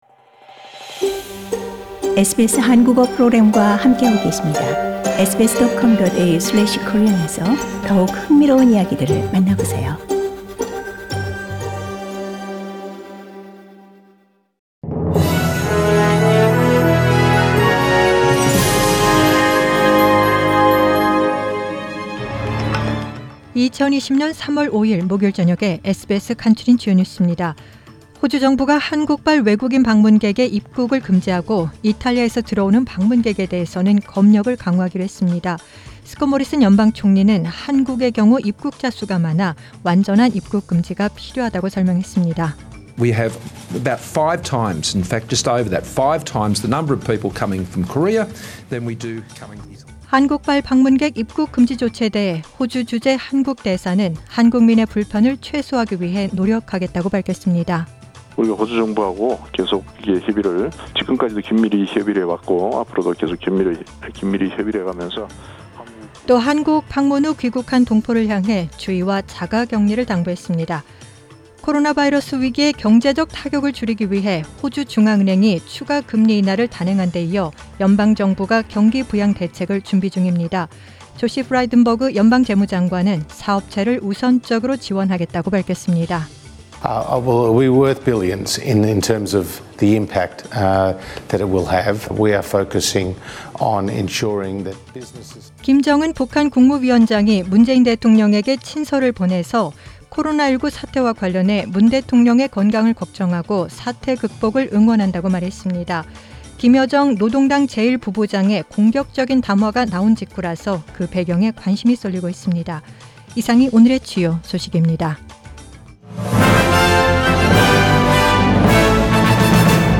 Find out Today’s top news stories on SBS Radio Korean.